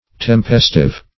Search Result for " tempestive" : The Collaborative International Dictionary of English v.0.48: Tempestive \Tem*pes"tive\, a. [L. tempestivus.]